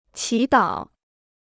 祈祷 (qí dǎo) Free Chinese Dictionary